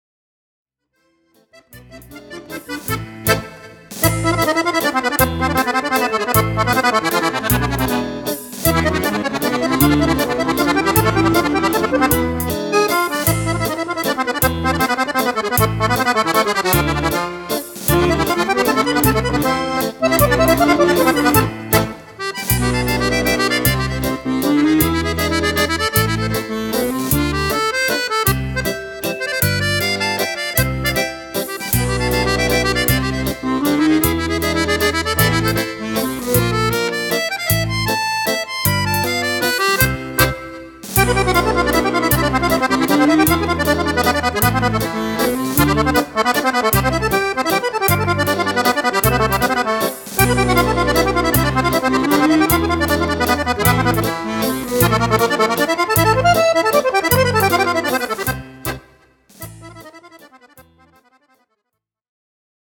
Mazurca
Fisarmonica
Strumento Fisarmonica (e Orchestra)